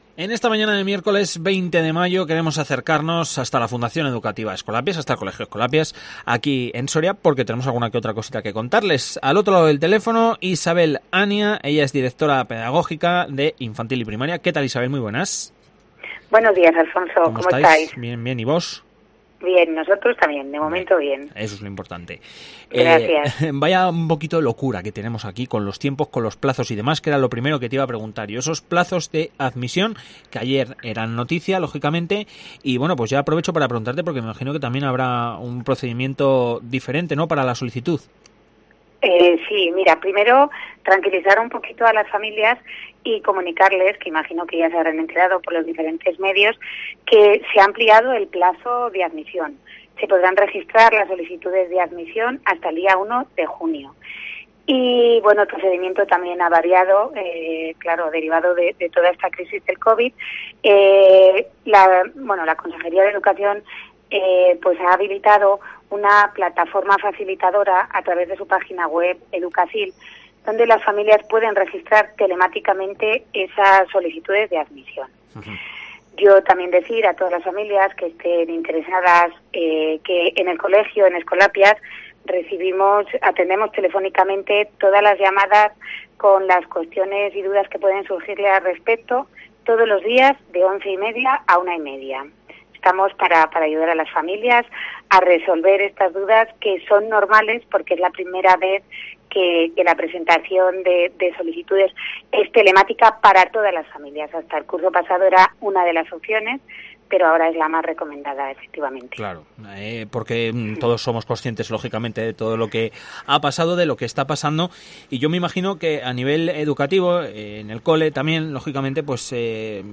ENTREVISTA - Colegio Escolapias. Soria.